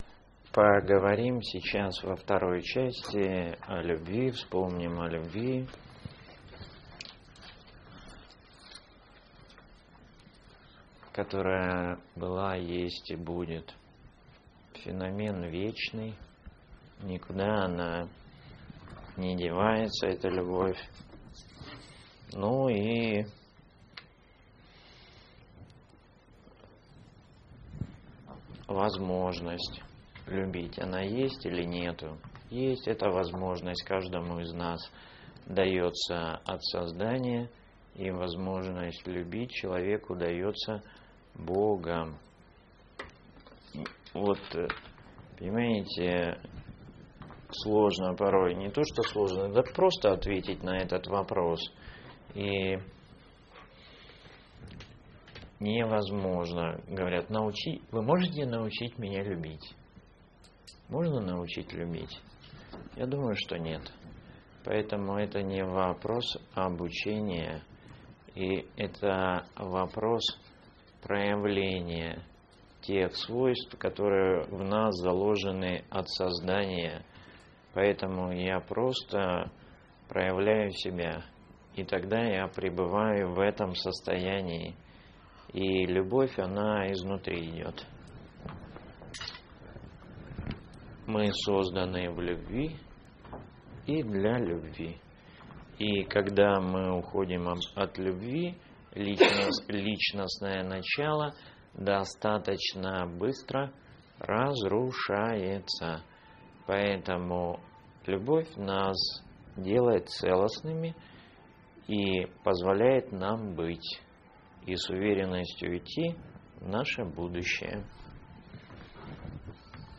Лекции Семинар